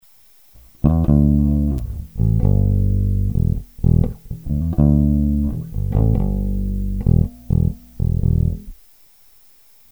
Треки записывал напрямую в линейный вход звуковой карточки без какой либо текущей и последующей обработки звука.
Bass track 6
Трек 6 - бас записан в "пассивном" режиме; при верхнем положении пуш-пула (преамп исключен из схемы баса и сигнал с хамбакера идет напрямую). Уровень сигнала с баса в пассивном режиме намного ниже, чем в активном, поэтому уровень трека приподнят при помощи нормалайза уже после записи трека. Сразу заметна разница в различии качества звучания - при выключенном преампе звук баса сильно потускнел, пропала яркость в звуке.
bass_track6.mp3